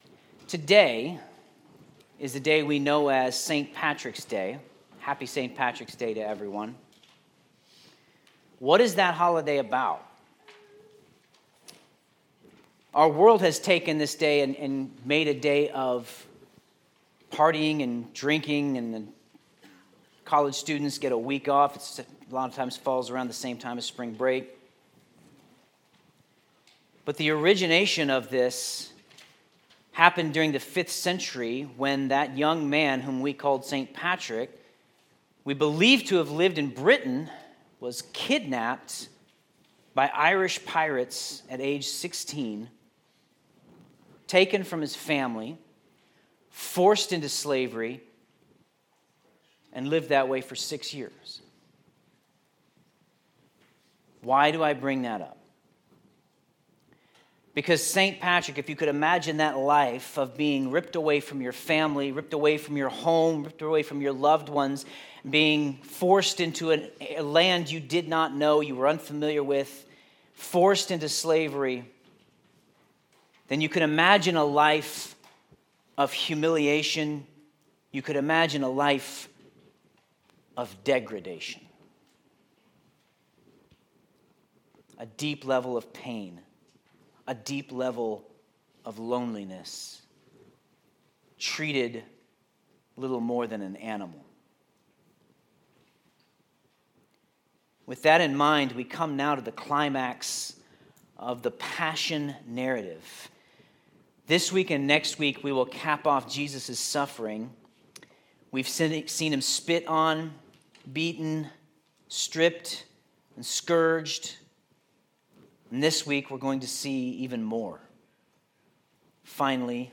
Sermon Notes Jesus was degraded on His way to the cross, but we see His matchless love for us in this.